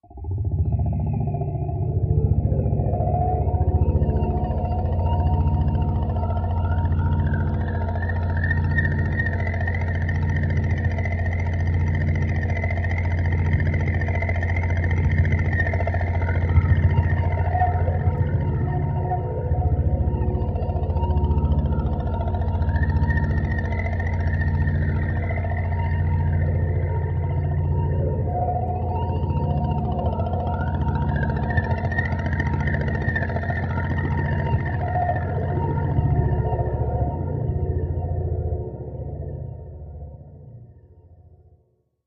Low Frequency Modulation 4; Extremely Low Frequency Rumble With Hiss / Whine In Background; Pulsating Slowly, Then Gradually Rising In Pitch And Tempo, Close Perspective.